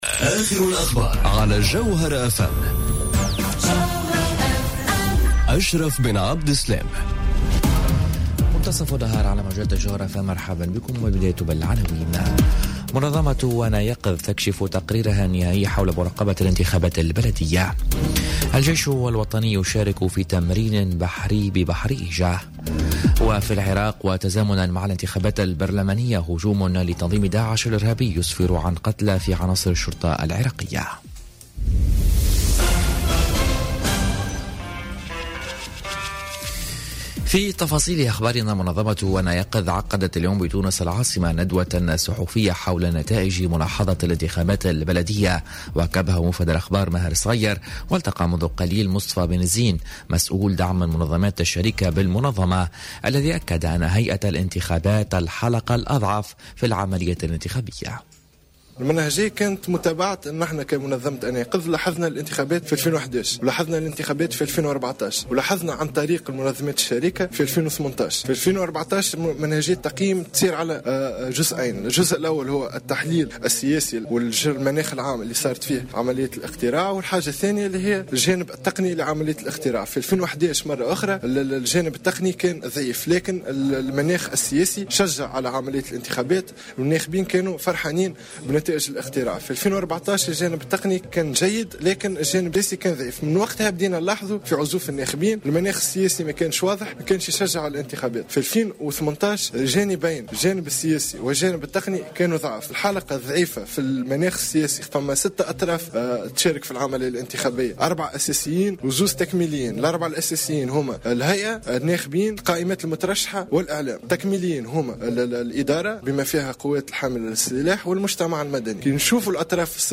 نشرة أخبار منتصف النهار ليوم السبت 12 ماي 2018